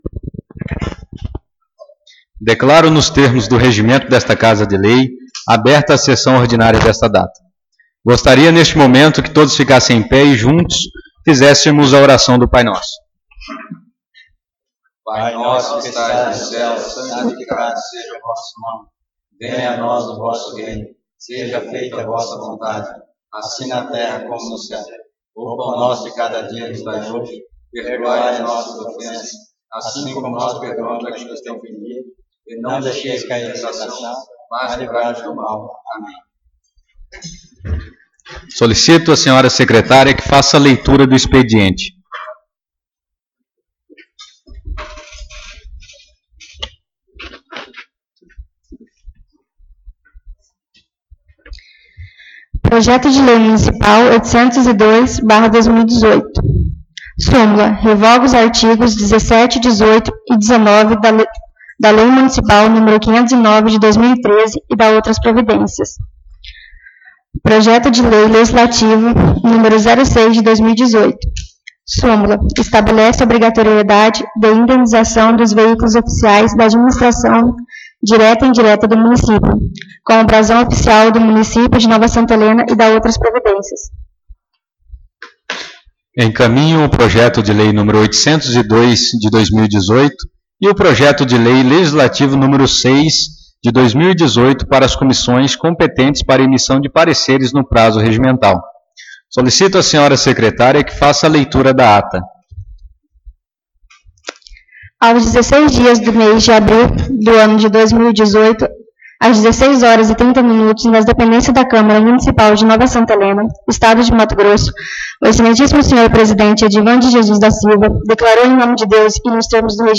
Sessão Ordinária 07/05/2018